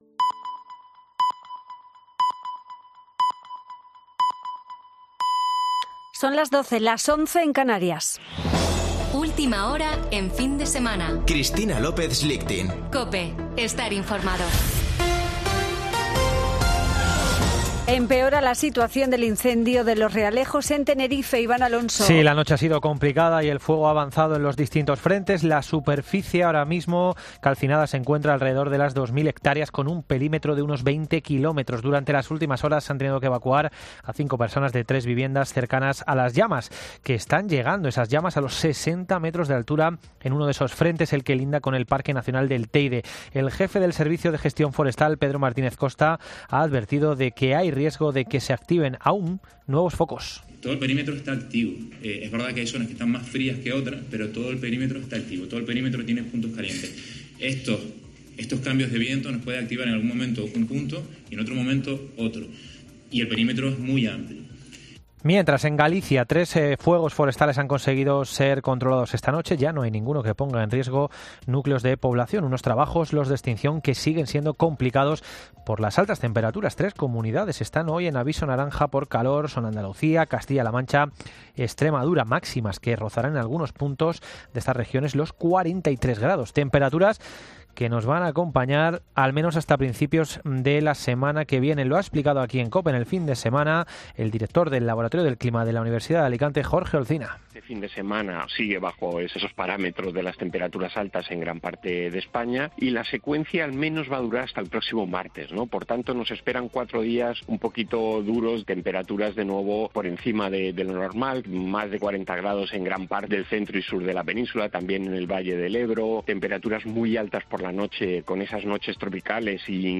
Boletín de noticias de COPE del 23 de julio de 2022 a las 12.00 horas